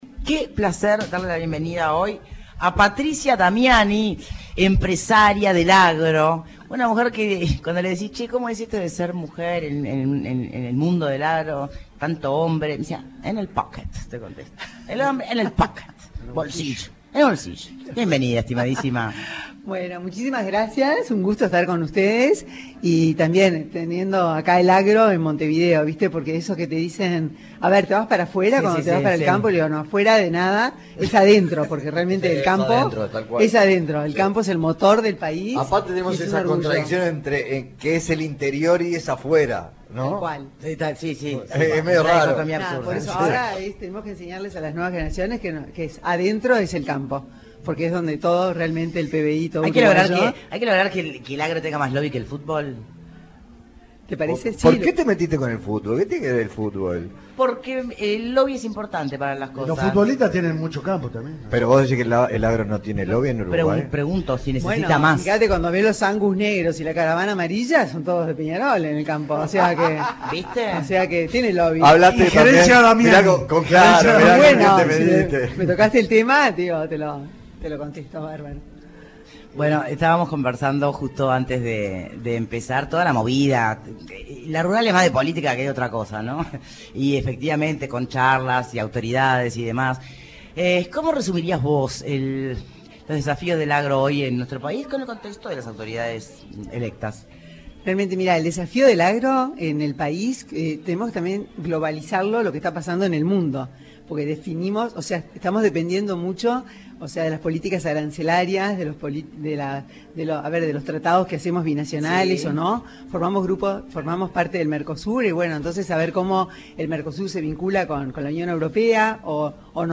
entrevista con Punto de Encuentro